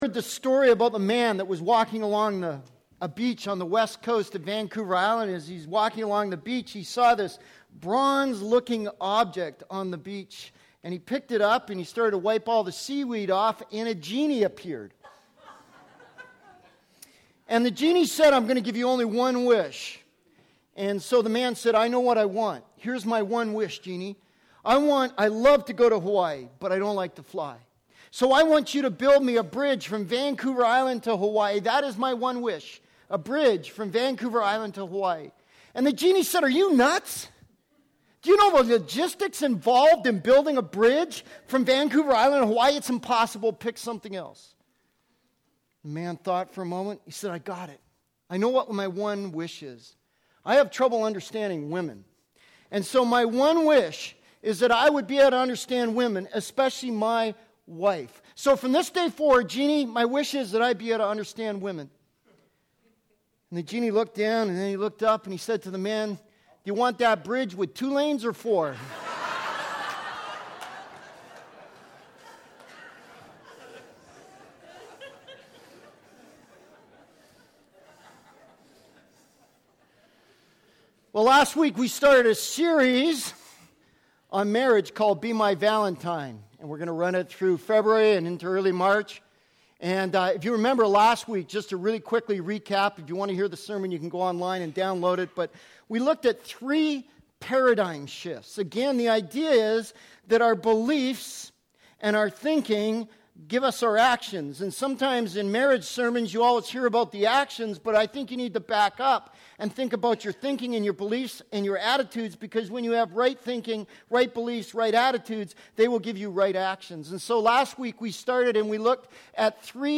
Sermons | Saanich Baptist Church
Sermon Series: Be My Valentine, The You Factor